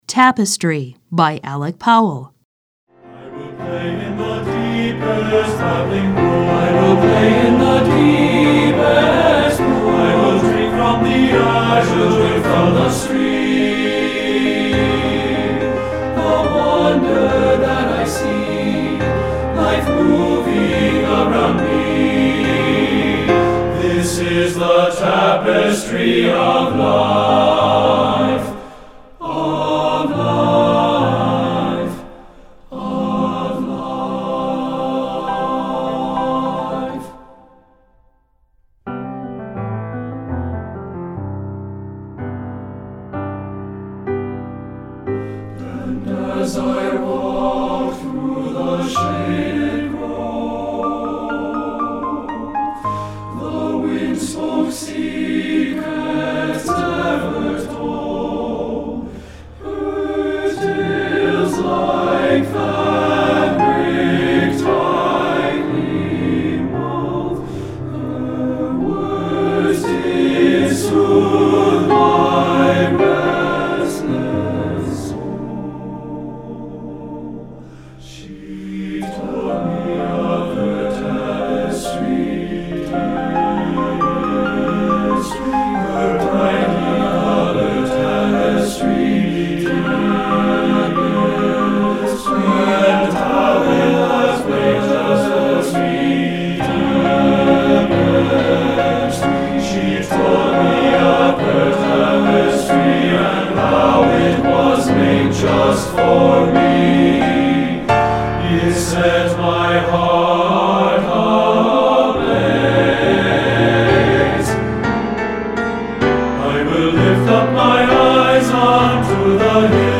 Choral Male Chorus
TTBB